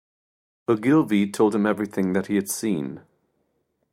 Read more Meaning A surname from Scottish Gaelic. Pronounced as (IPA) /ˈəʊɡlvɪ/ Etymology Variant of Ogilvie.